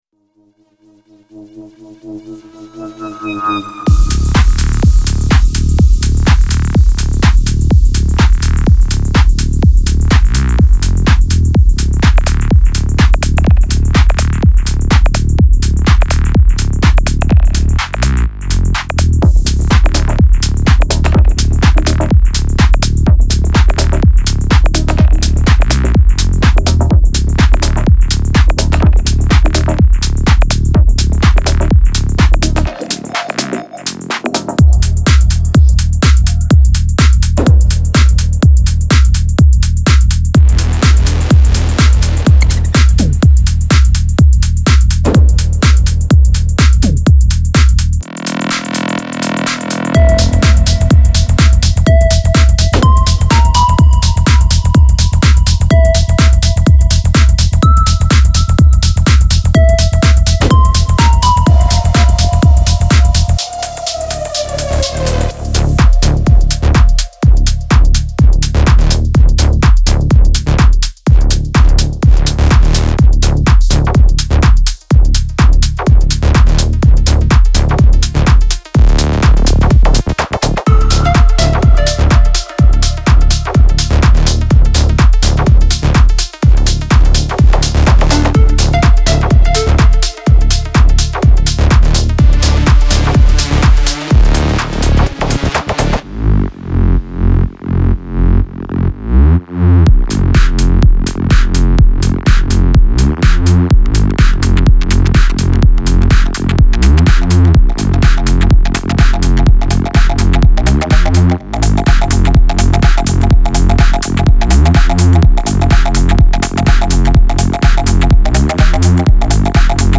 melodic loops for techno and underground